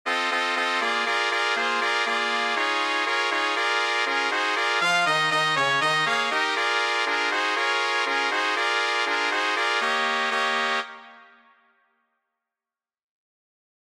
Key written in: B♭ Major
How many parts: 4
Type: Female Barbershop (incl. SAI, HI, etc)
All Parts mix: